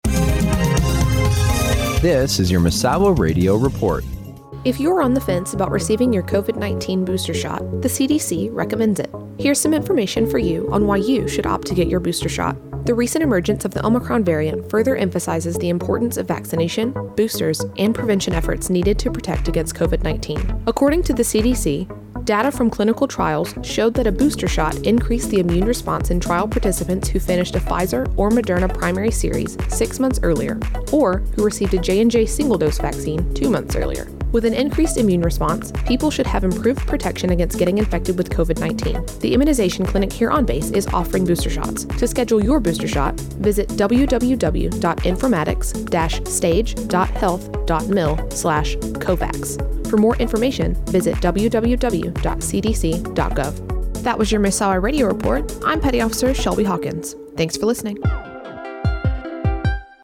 Misawa Radio Report - Booster Shots